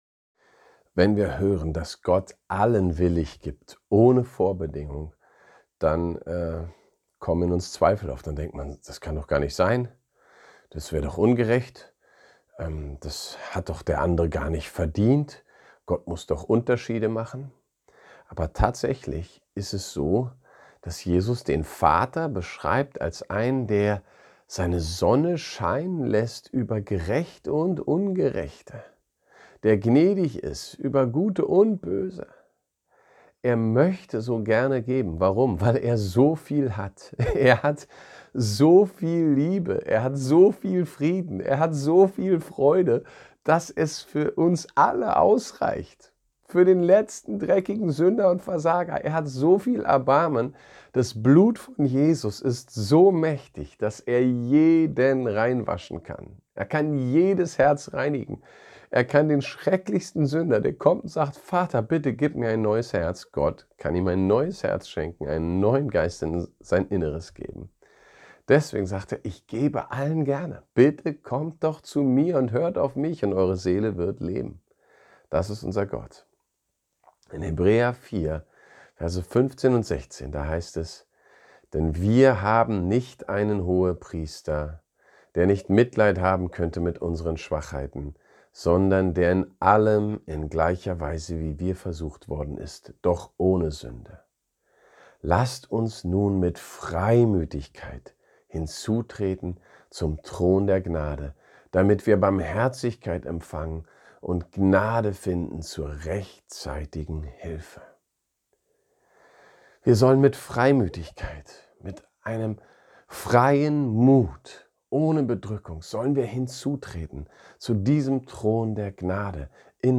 kraftvolles Gebet
Gebet_7.m4a